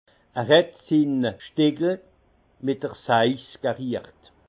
Haut Rhin
Ville Prononciation 68
Munster